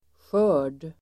Uttal: [sjö:r_d]